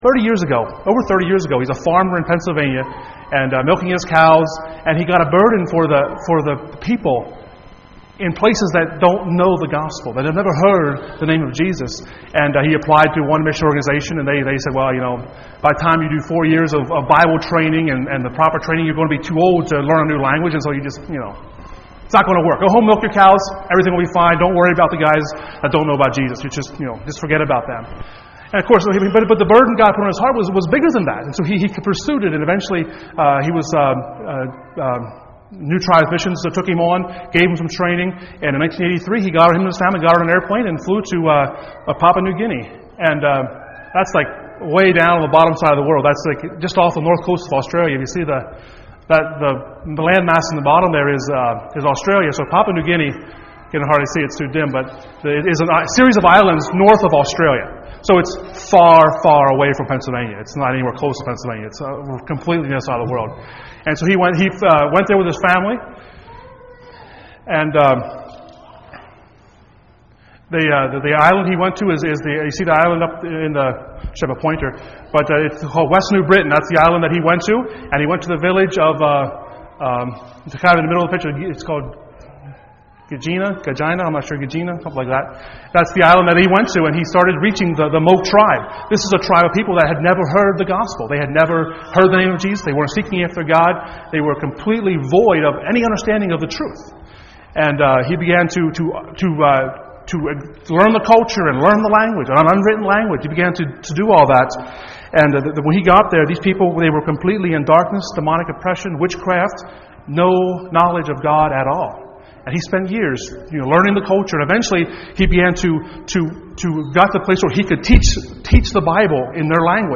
Sunday Morning Studies in Colossians Passage: Colossians 1:15-20 Service Type: Sunday Morning %todo_render% « Jesus